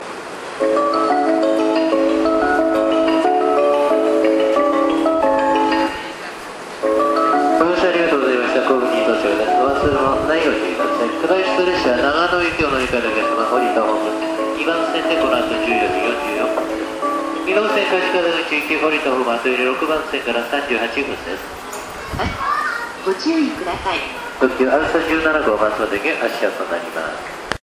発車メロディー３コーラスです。特急電車で収録しました。
音質が非常に高音質です。